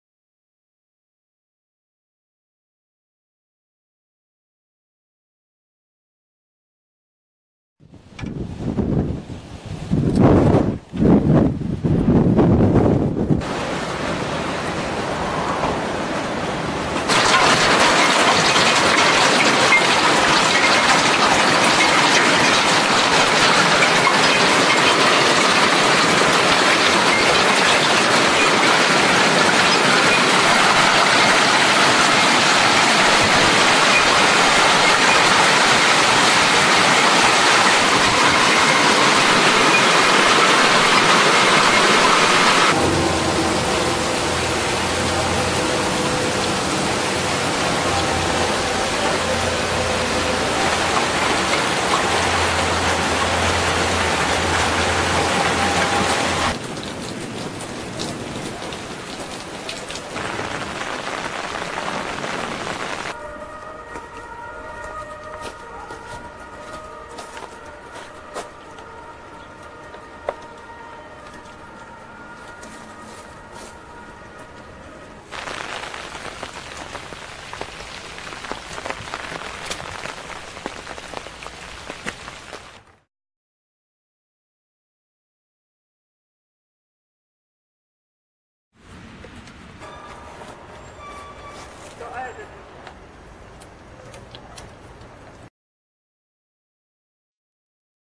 Hagelsturm 21.07.1998